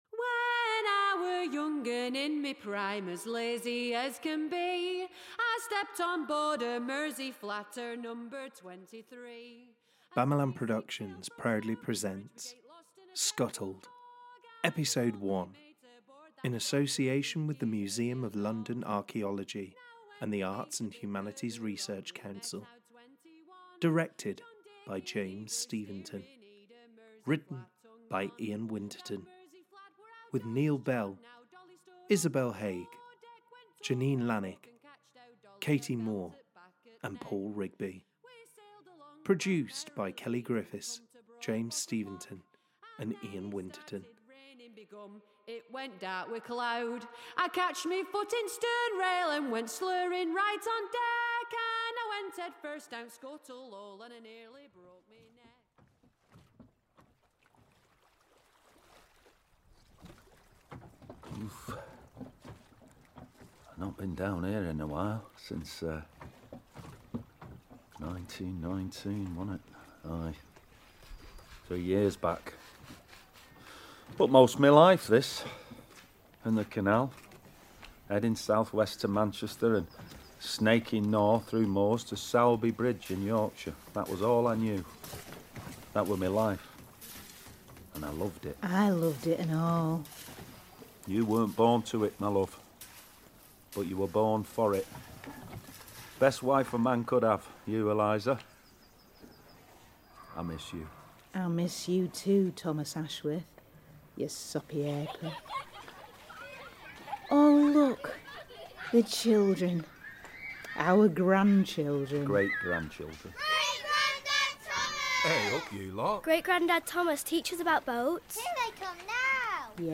Recorded at: Oscillate Studios (Manchester), Jungle Studios (Soho, London), and Voltalab (Rochdale).